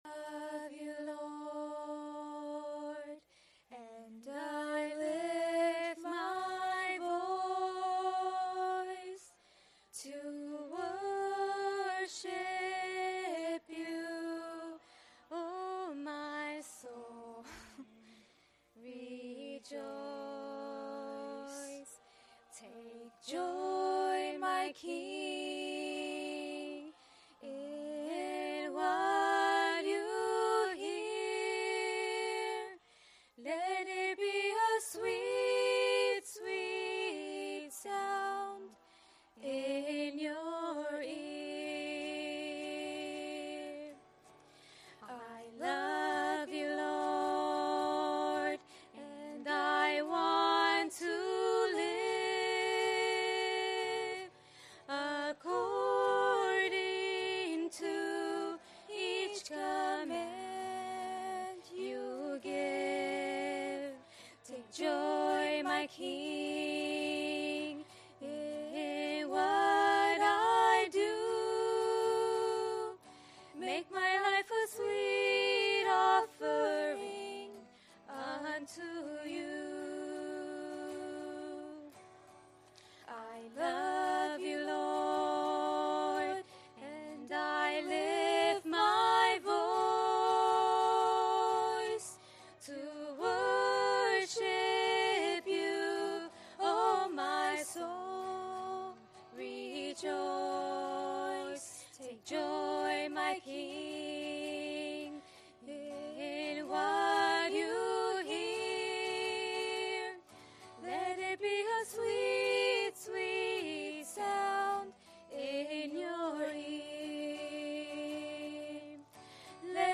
A message from the series "Focused on the Family."